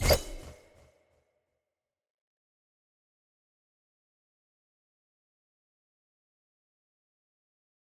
Spirit slash.wav